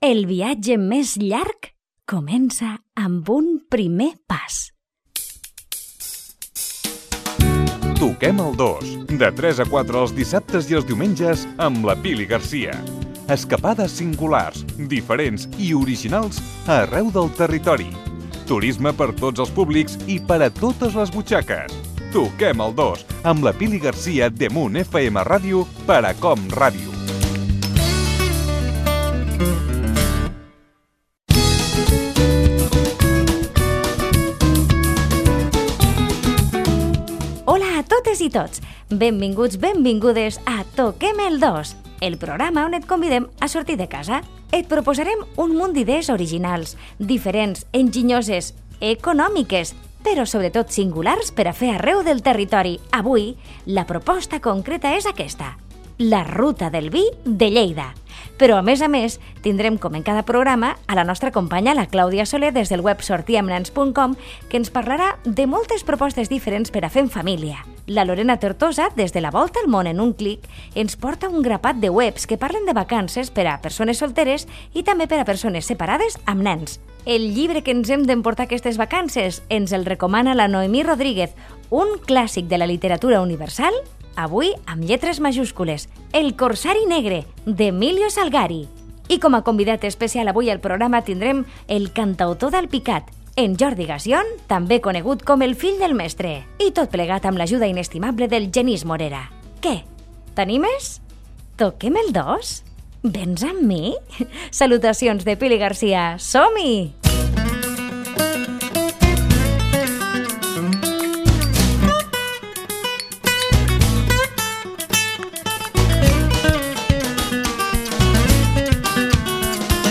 Careta, inici i sumari del programa.
Entreteniment